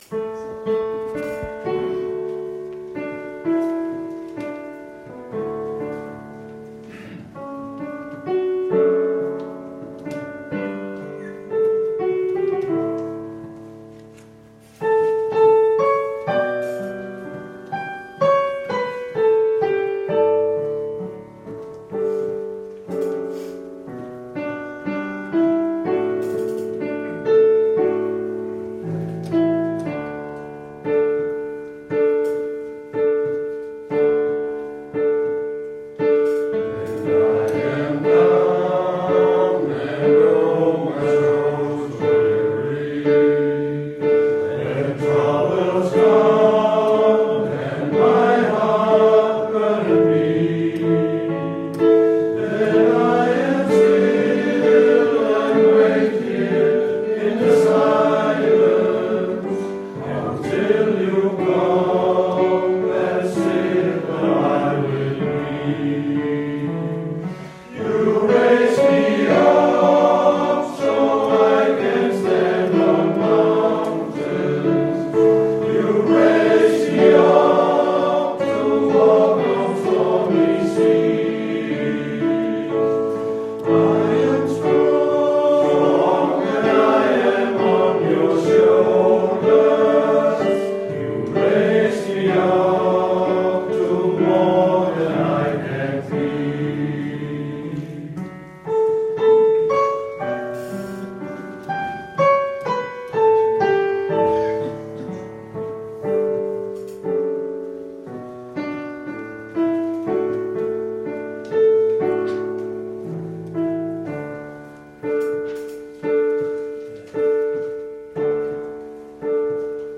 Øveaften 19. oktober 2020
Atter en stor flok veloplagte sangere mødte op til øveaften på Industrivej.